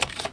Keycard Lock Slide Sound
household